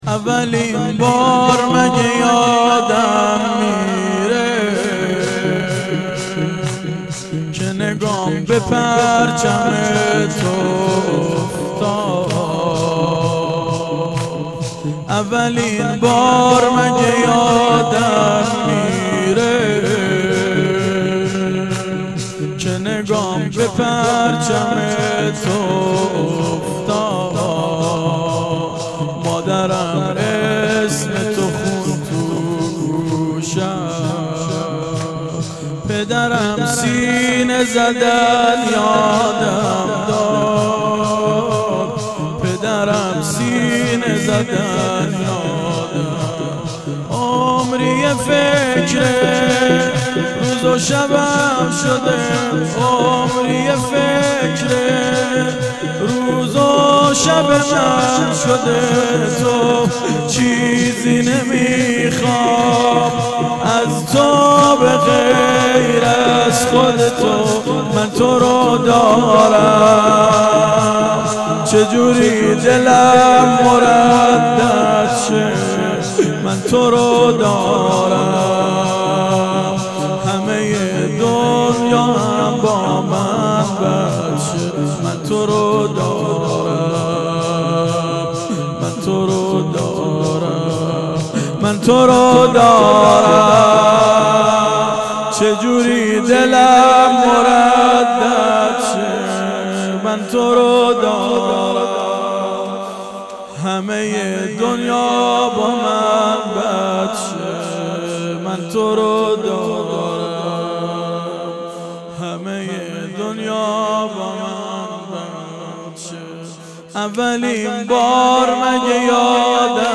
شور شب یازدهم محرم 98